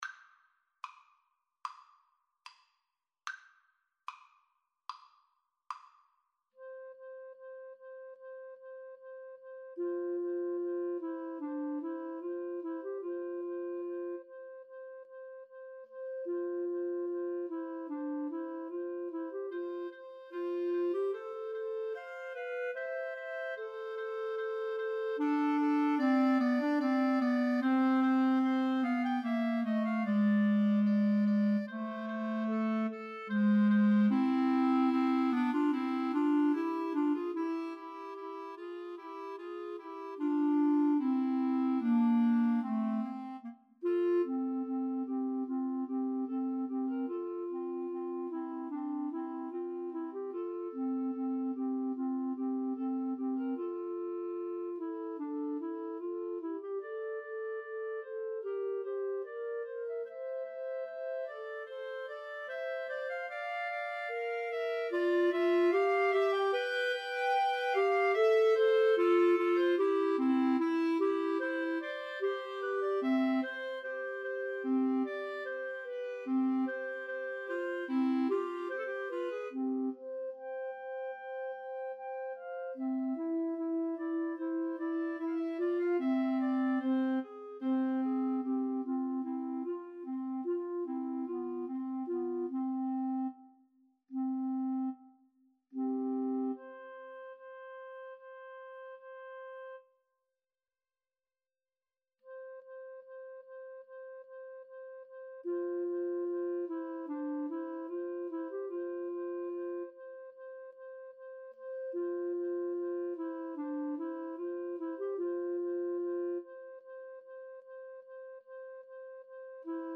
~ = 74 Moderato
Clarinet Trio  (View more Intermediate Clarinet Trio Music)
Classical (View more Classical Clarinet Trio Music)